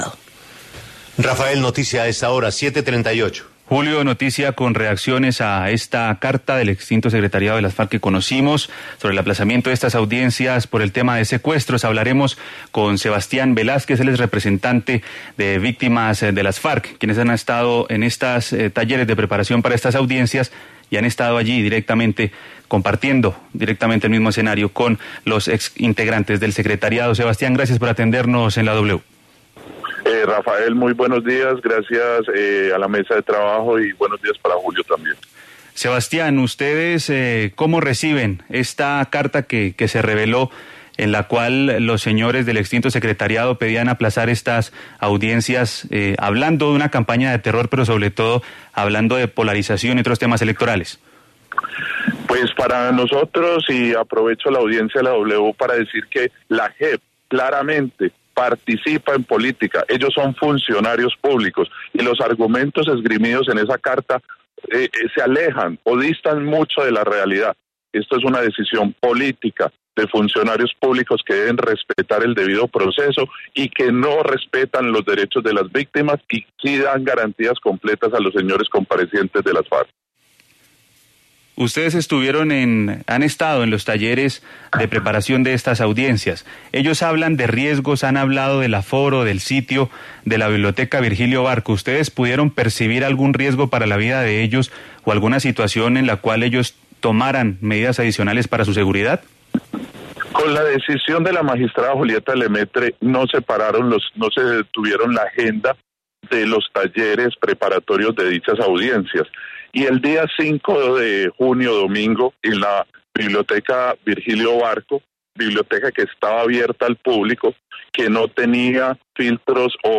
En entrevista con La W